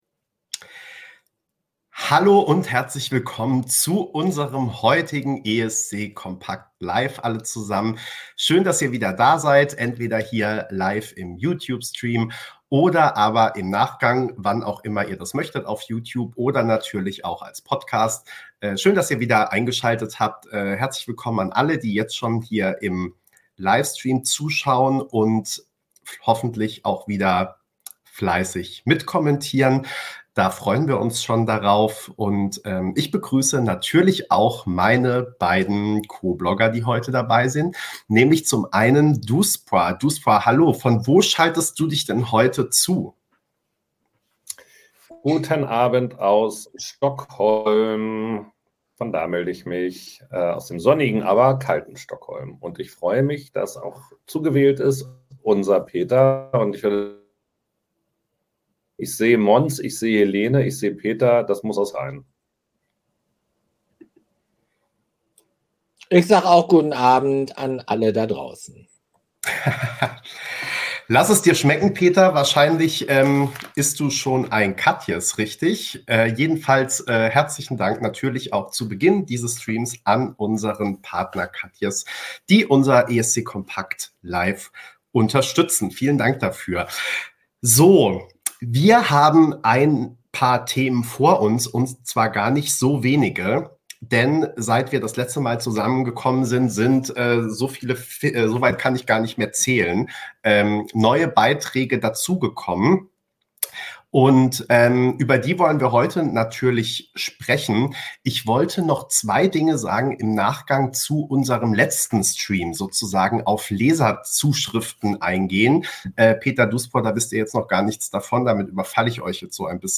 ESC kompakt LIVE